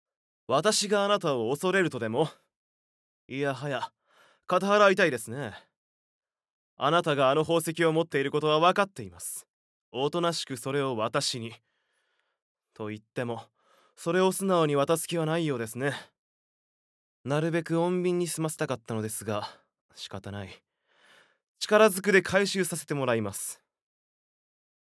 ボイスサンプル
怪盗